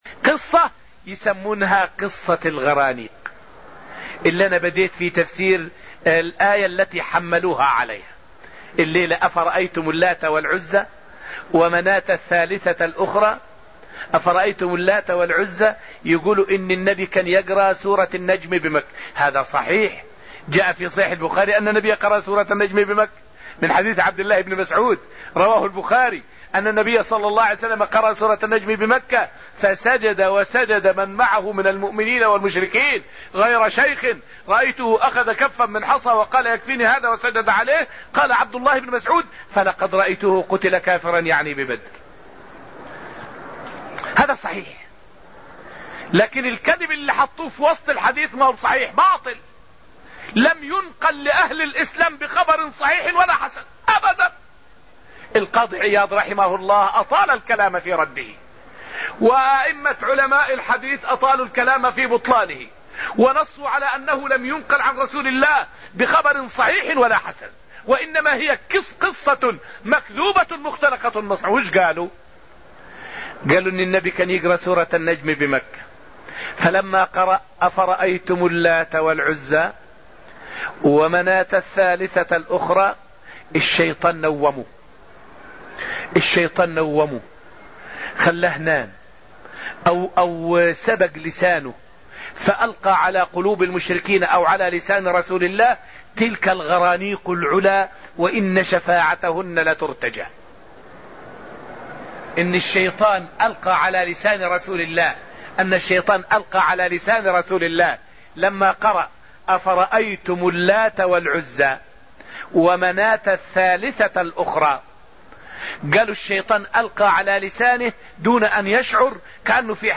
فائدة من الدرس السادس من دروس تفسير سورة النجم والتي ألقيت في المسجد النبوي الشريف حول قصة الغرانيق المفتراة على النبي صلى الله عليه وسلم والرد عليها.